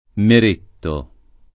— con -e- aperta la pn. loc. (vaianese), conosciuta solo in luogo